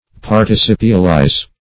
Search Result for " participialize" : The Collaborative International Dictionary of English v.0.48: Participialize \Par`ti*cip"i*al*ize\, v. t. [imp.